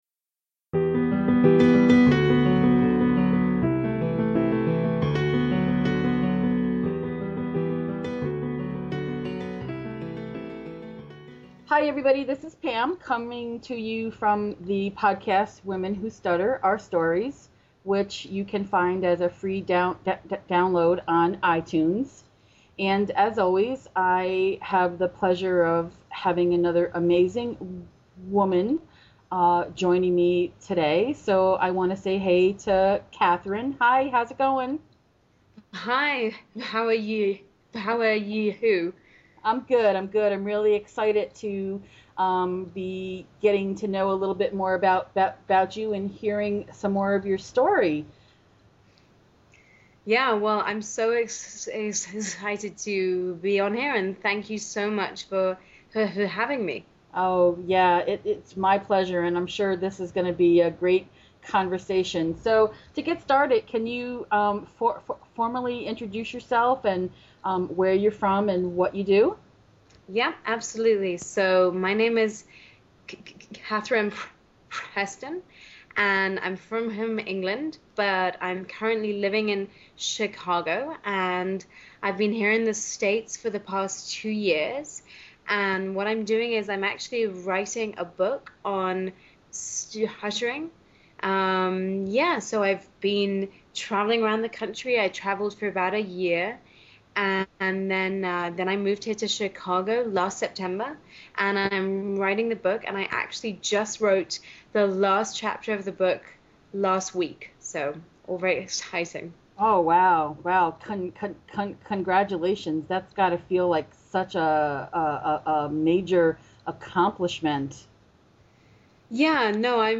She mentioned she was writing a book and I mentioned that I had recently started this podcast for women who stutter to share their stories. We knew there and then that we would eventually have this conversation!